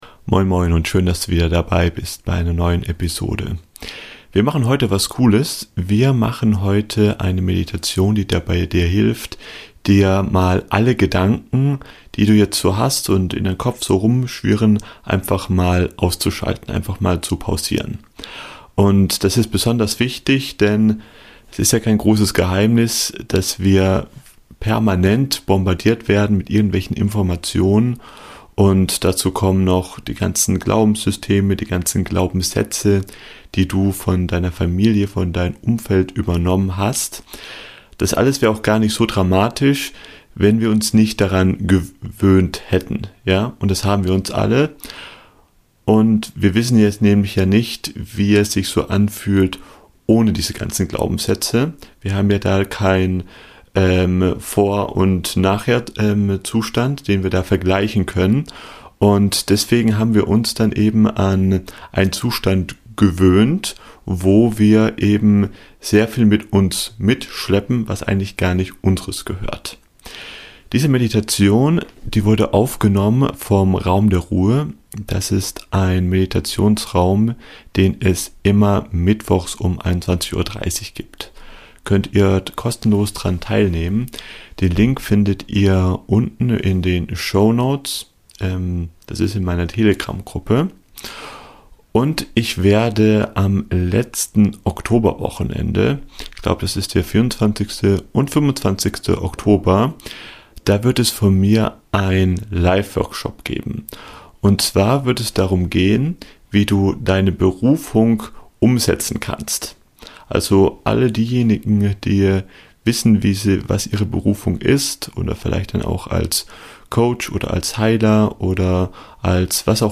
In dieser Episode lade ich dich zu einer geführten Meditation ein, die dir hilft, den endlosen Strom der Gedanken zu unterbrechen und einen Moment echter Stille zu erleben.